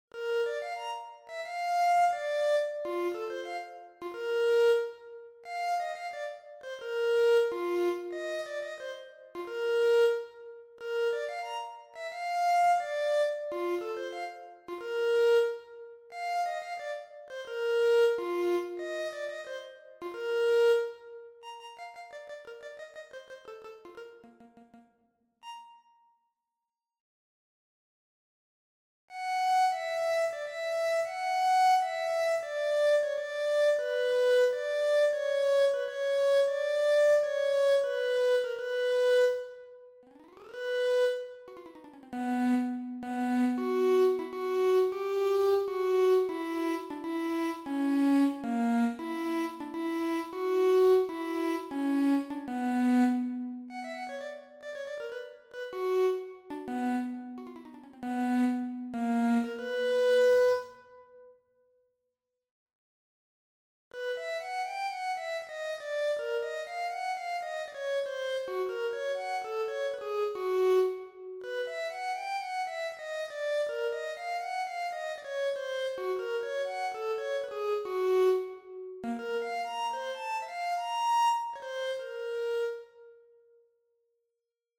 The glissotar is a newly invented instrument that is a version of the Hungarian woodwind instrument "tarogato" but with the distinction that the notes are not determined by holes as in most woodwind instruments bu...